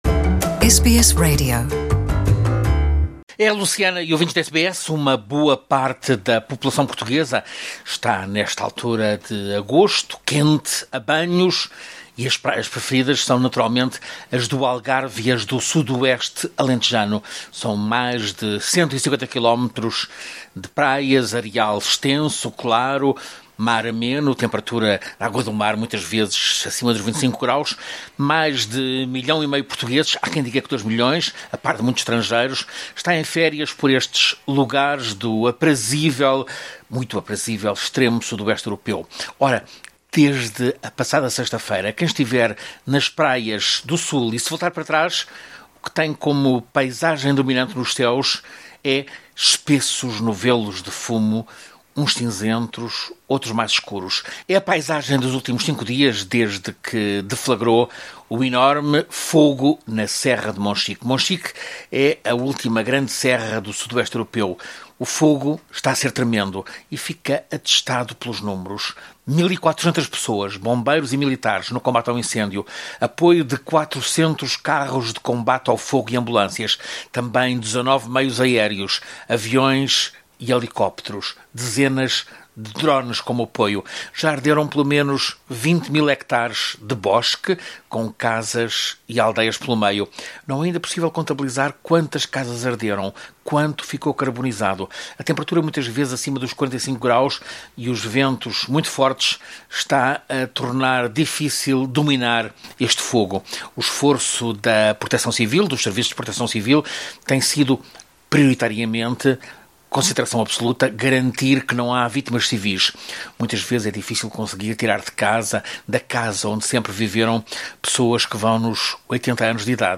O incêndio continua entre Casais e a Barragem de Odelouca.